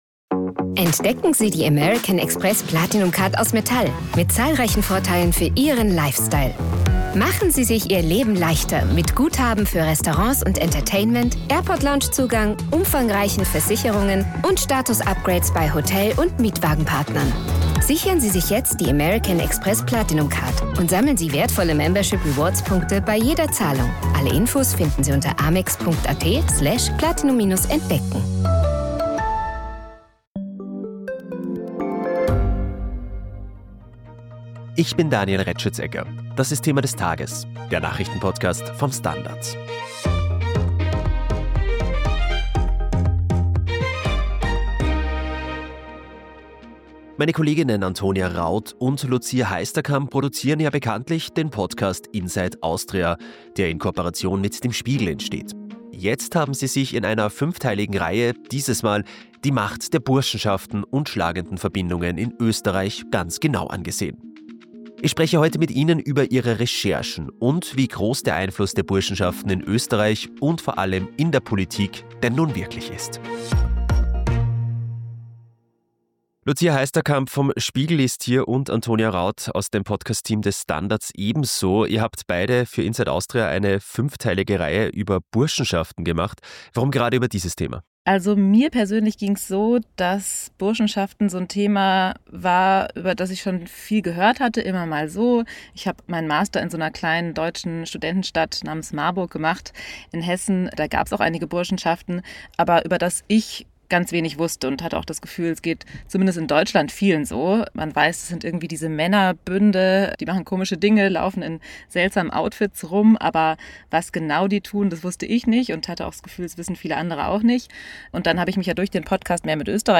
Wir sprechen mit den Kolleginnen von Inside Austria über ihre aktuelle Recherche zur Macht der Burschenschaften in Österreich